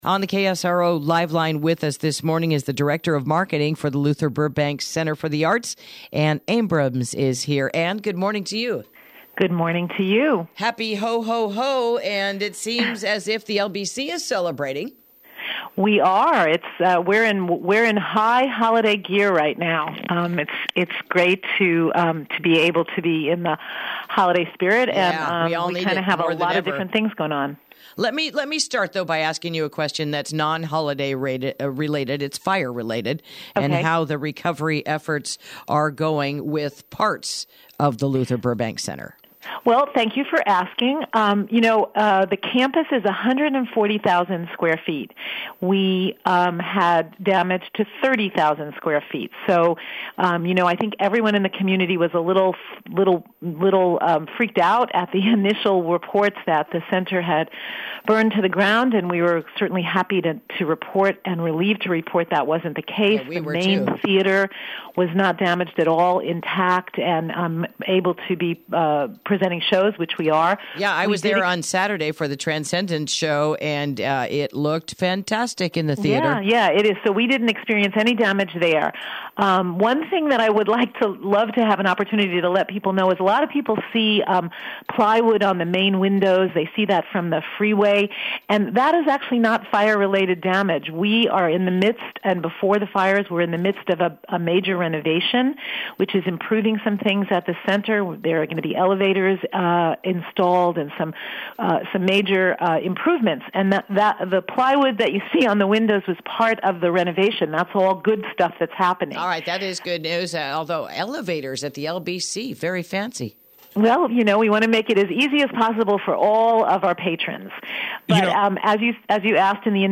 Interview: Holiday Events and Shows at the Luther Burbank Center